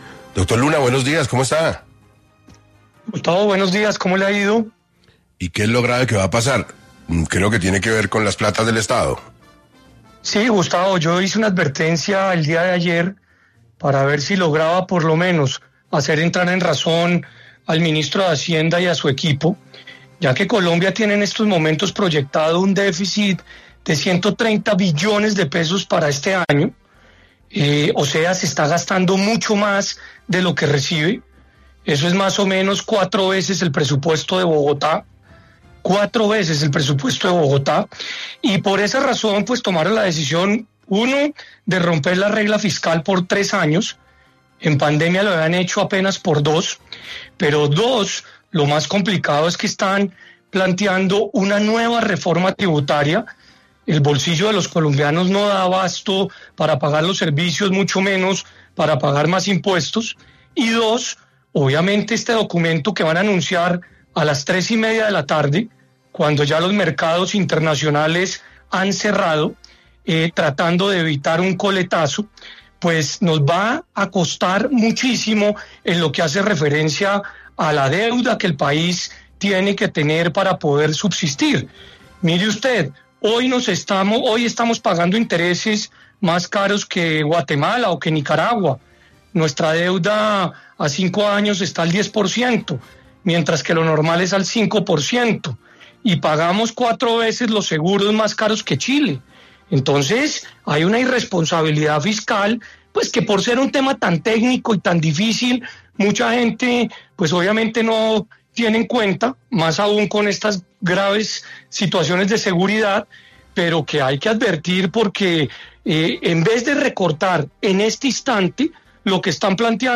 En entrevista con 6AM de Caracol Radio, Luna criticó la decisión del gobierno de Gustavo Petro de romper la regla fiscal y la posible implementación de una nueva reforma tributaria.